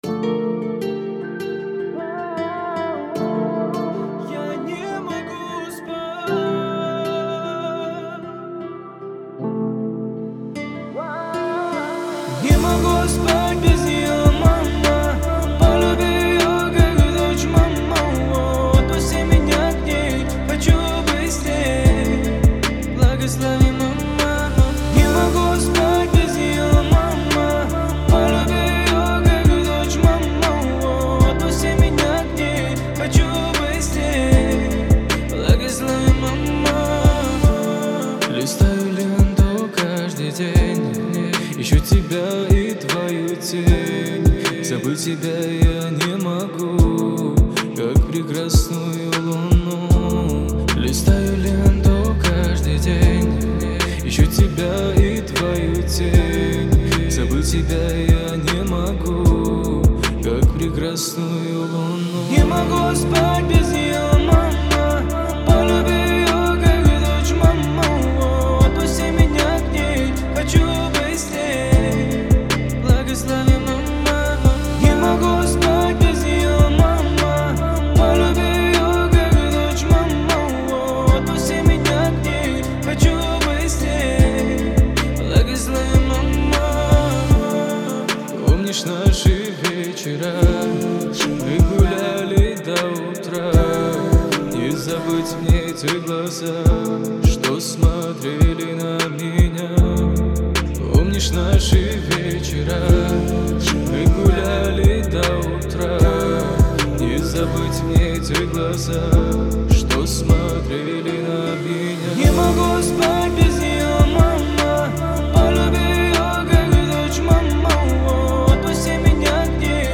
обладает меланхоличным настроением